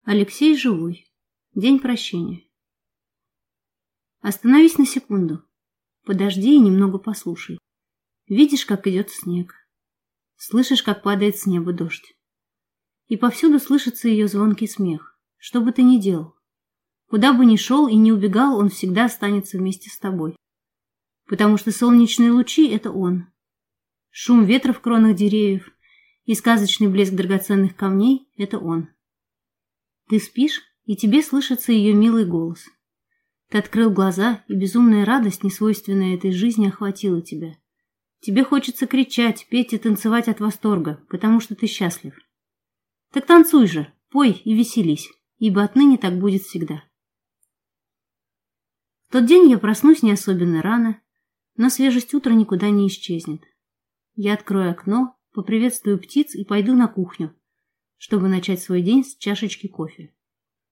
Аудиокнига День прощения | Библиотека аудиокниг